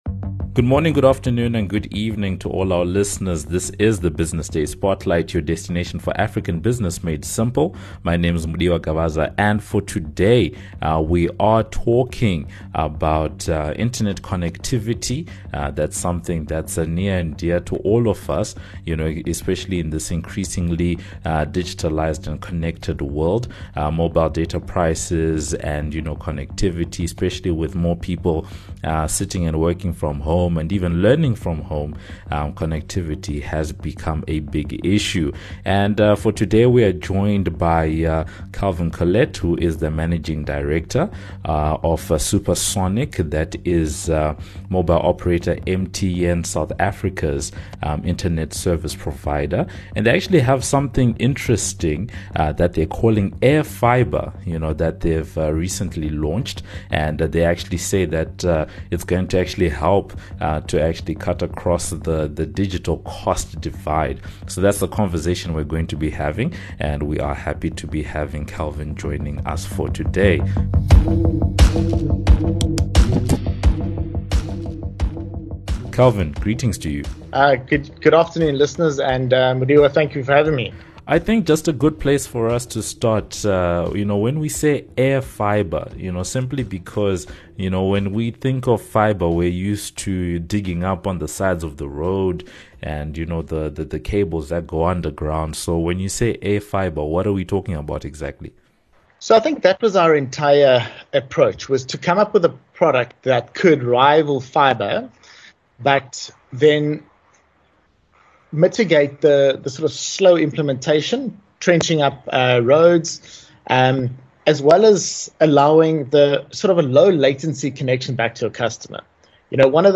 Listen in to hear the full discussion and thoughts around these and other questions.